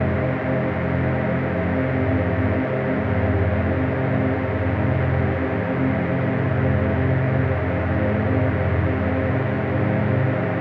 Fm-PadAtmos01.wav